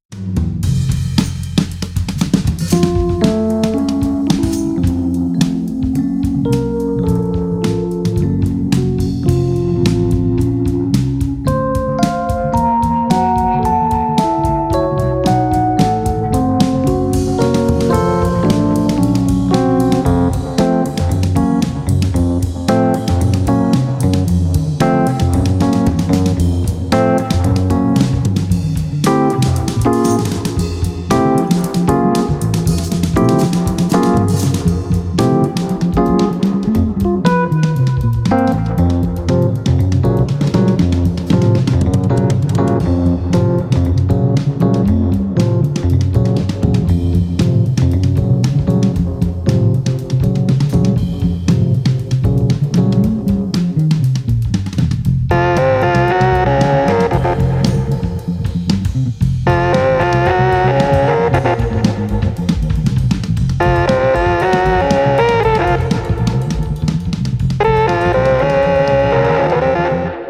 Genre: Rock, Jam Band, Americana, Roots.